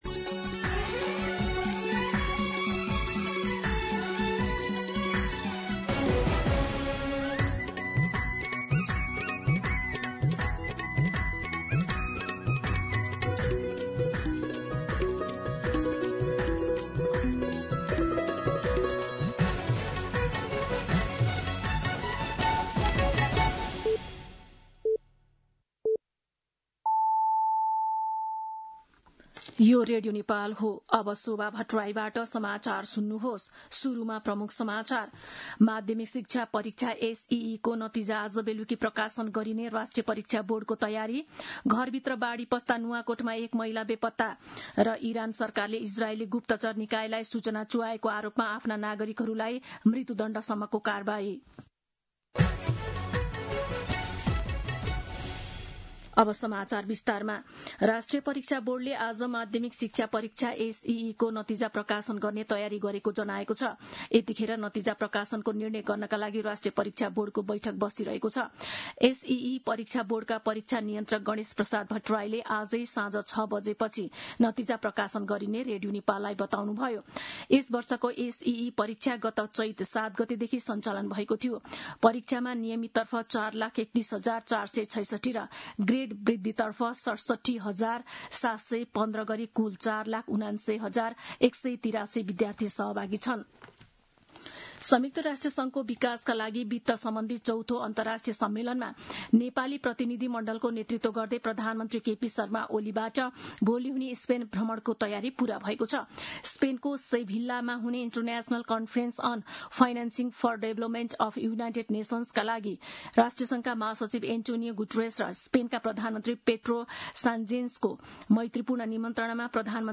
दिउँसो ३ बजेको नेपाली समाचार : १३ असार , २०८२